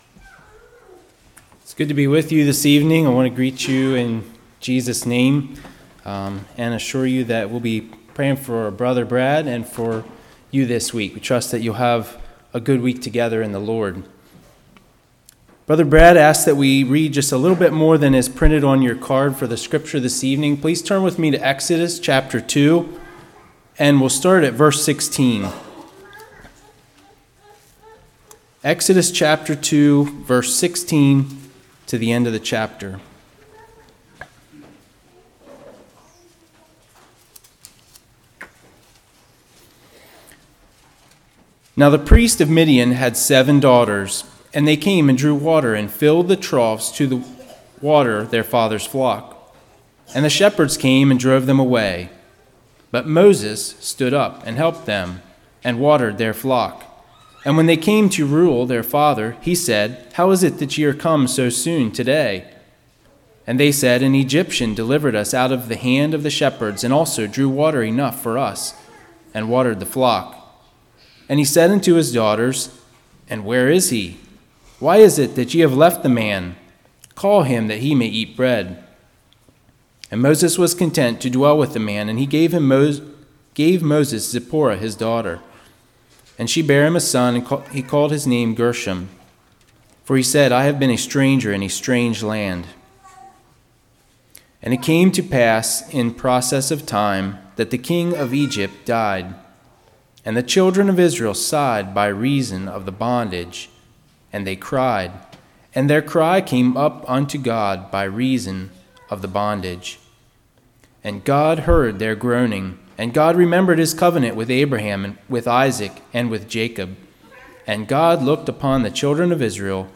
Exodus 2:16-25 Service Type: Revival The Answer May Not Be immediate.